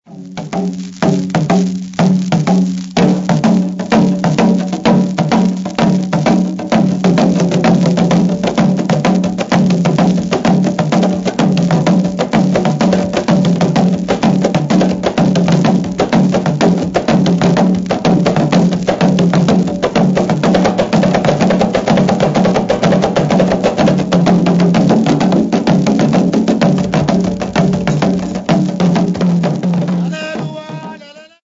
Folk music
Field recordings
sound recording-musical